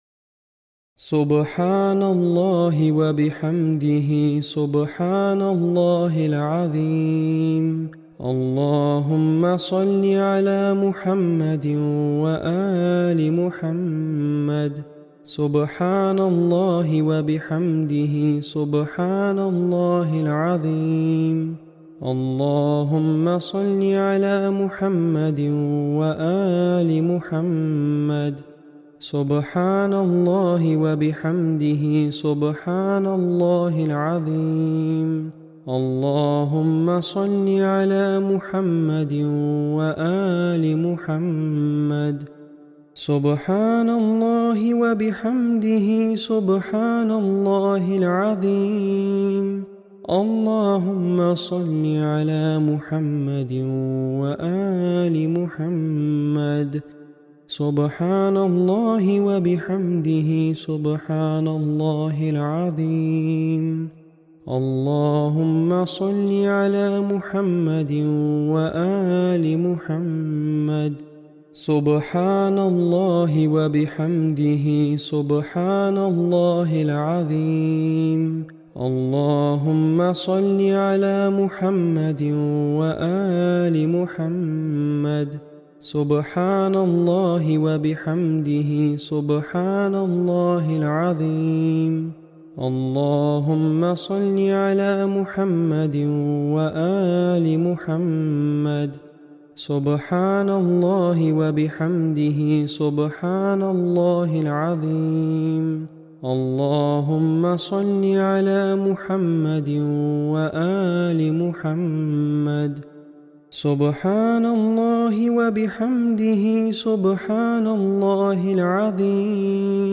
Loop of prayers instructed by Huzoor(aba) on Friday Sermon on 23rd August 2024 which should be recited daily. Loop contains 20 repetitions of Subhanallah, 10 of Astaghfar and 10 of Rabikulu..